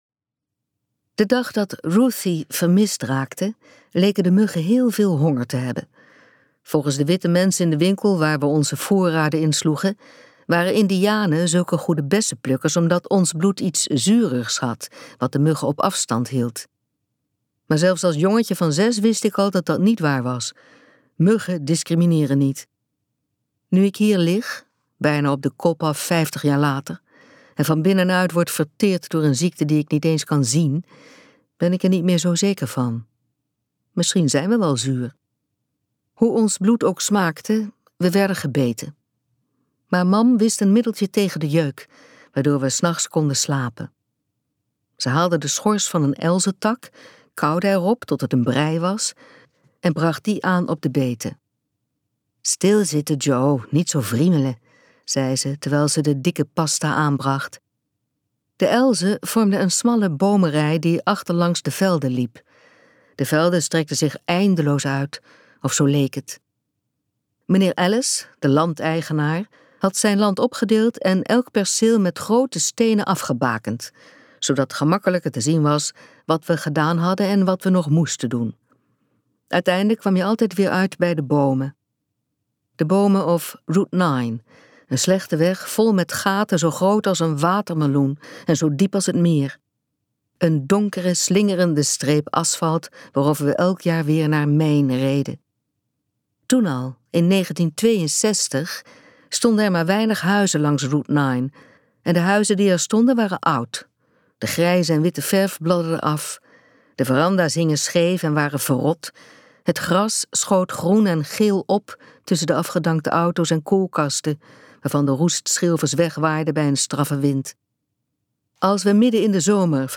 Ambo|Anthos uitgevers - De bessenplukkers luisterboek